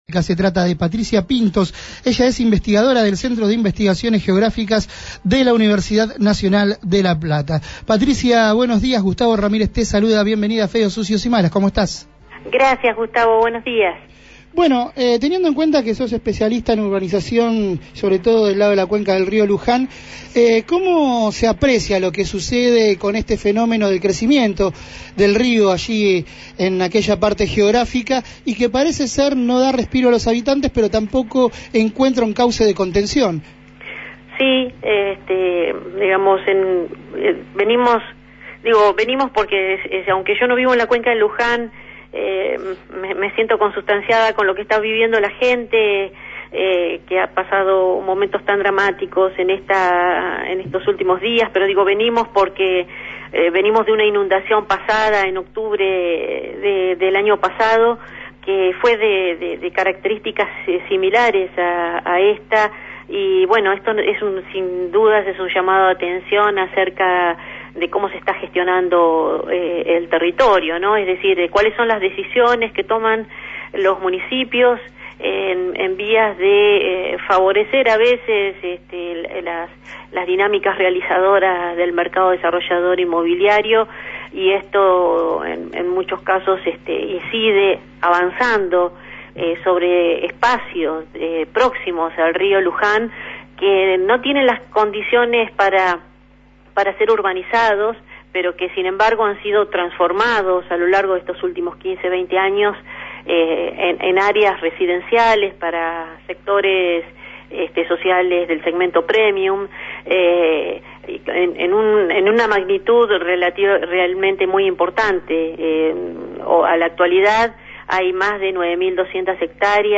dialogó en Feos, Sucios y Malas sobre las recientes inundaciones.